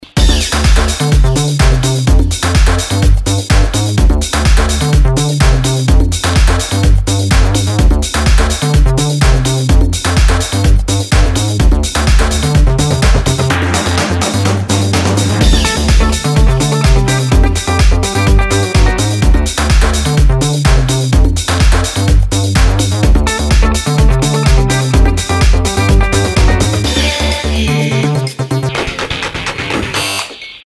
Танцевальные
house, club, dance, без слов